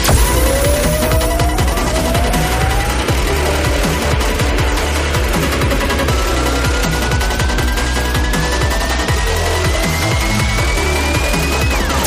Super_Mega_Win_Sound.mp3